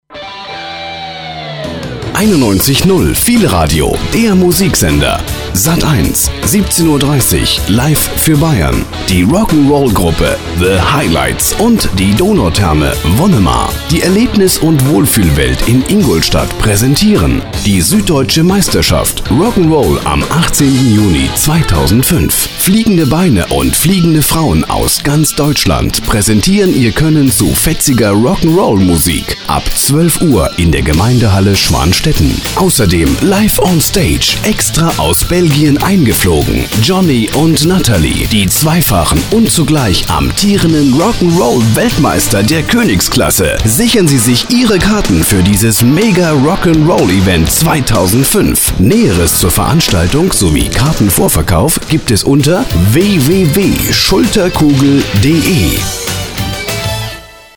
Ausgebildeter Sprecher und Moderator TV Rundfunk Kino Multimedia
Sprechprobe: Industrie (Muttersprache):
voice over german